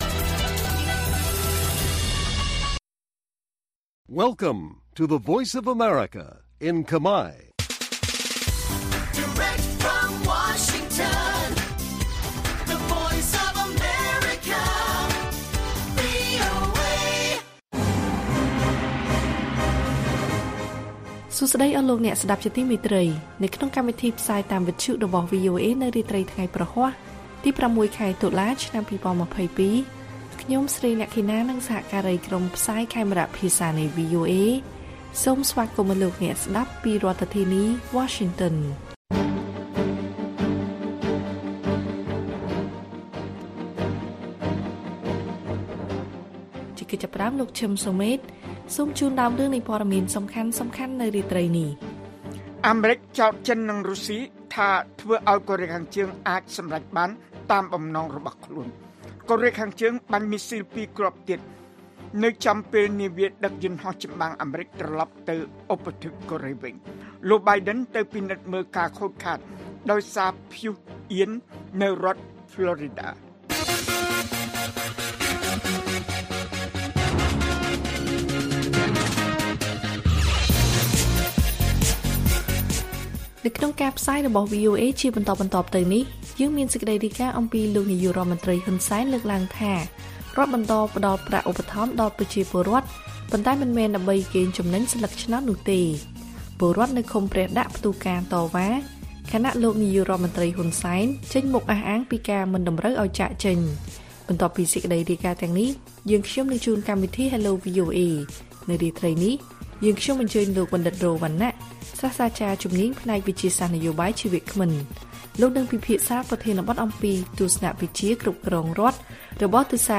ព័ត៌មានពេលរាត្រី ៦ តុលា៖ លោក ហ៊ុន សែន ថារដ្ឋបន្តផ្តល់ប្រាក់ឧបត្ថម្ភដល់ពលរដ្ឋ ប៉ុន្តែមិនមែនដើម្បីកេងចំណេញសន្លឹកឆ្នោតឡើយ